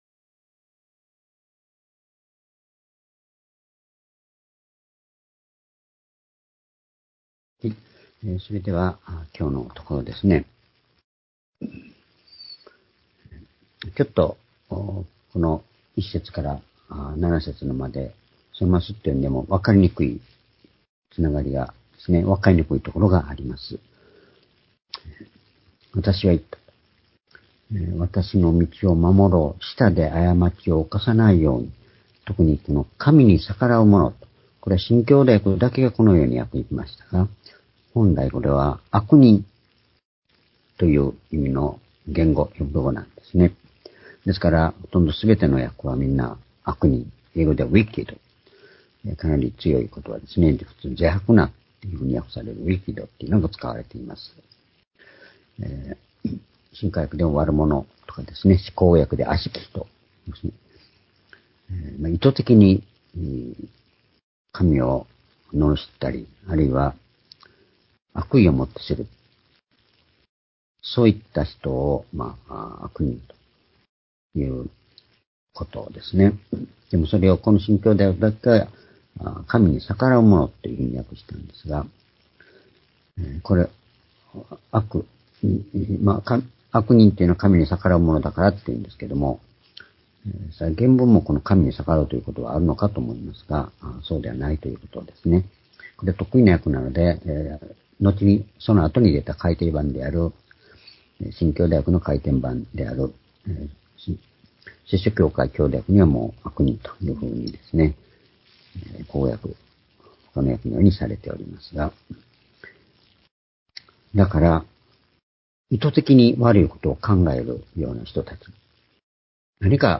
（主日・夕拝）礼拝日時 ２０２３年１２月5日（夕拝） 聖書講話箇所 「悪しき者にうち勝つ道―空しさから信仰へ」 詩編39の1-7 ※視聴できない場合は をクリックしてください。